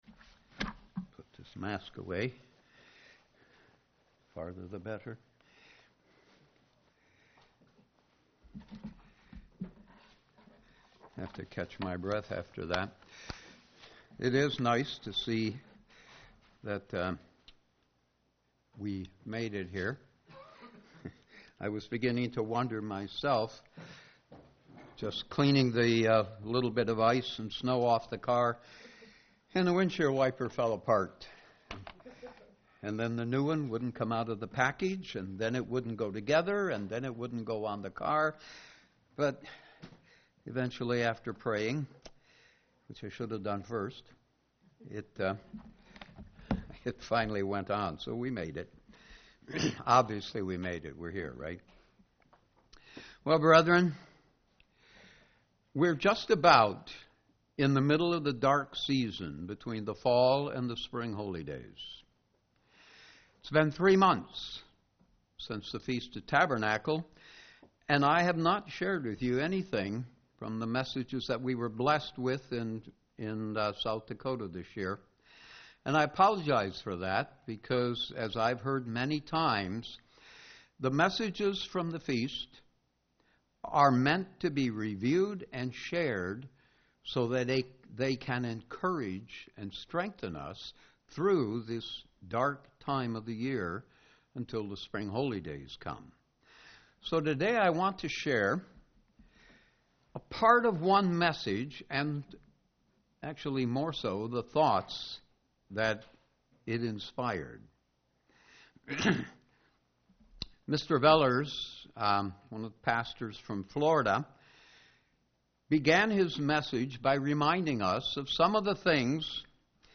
Sermons
Given in Ann Arbor, MI Detroit, MI